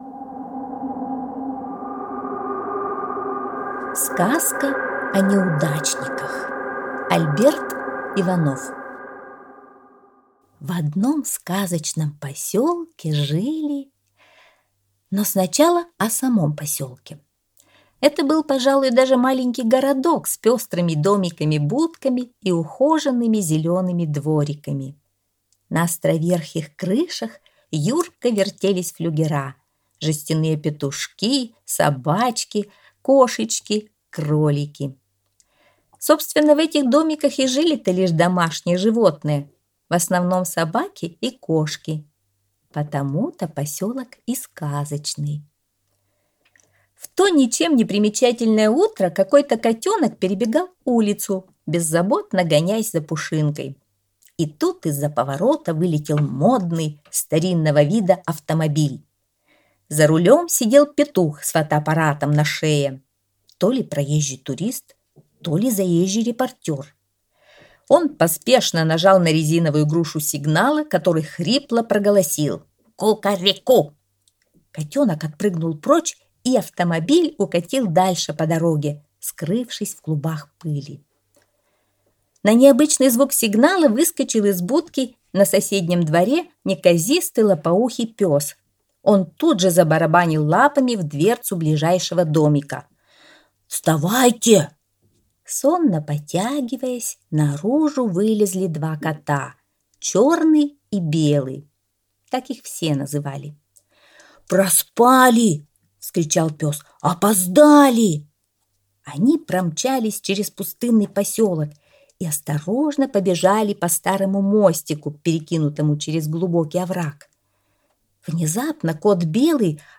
Сказка о неудачниках - аудиосказка Альберта Иванова - слушать онлайн